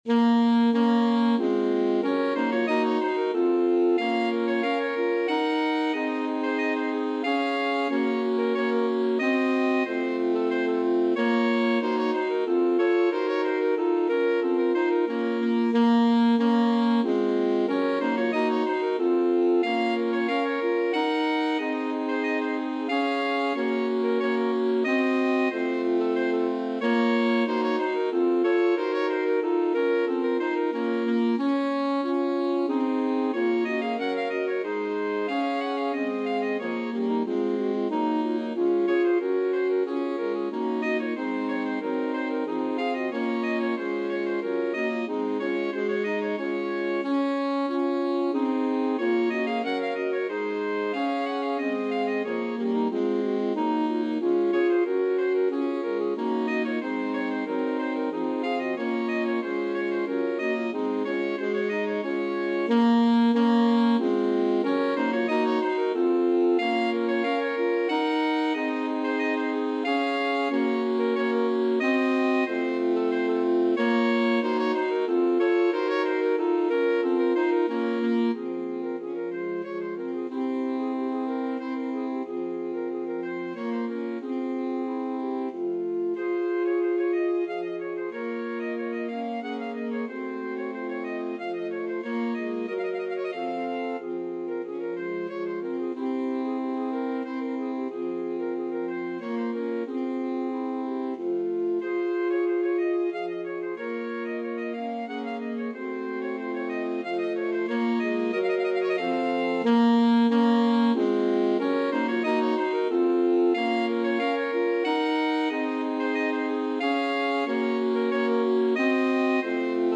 3/2 (View more 3/2 Music)
Allegro Moderato = c. 92 (View more music marked Allegro)
Classical (View more Classical Alto Sax Quartet Music)